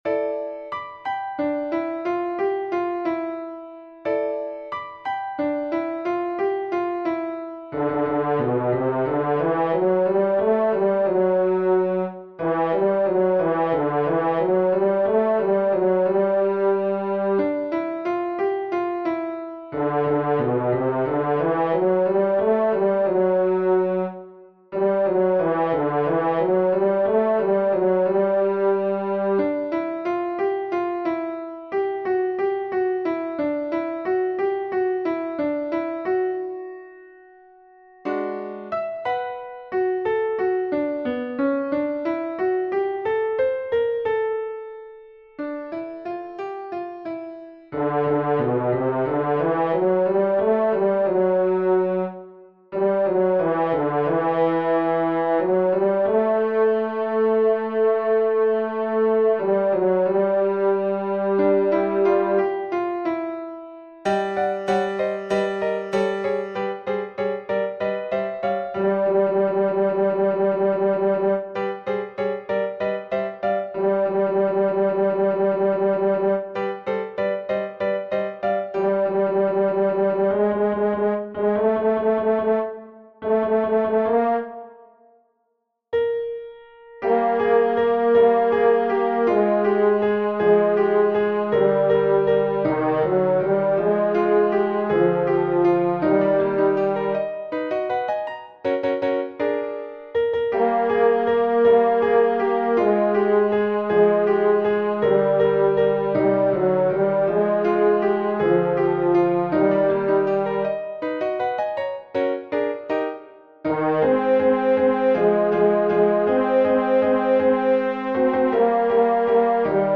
Voice part practice (up to Letter I, page 11):
The featured voice is a horn.
TENOR 2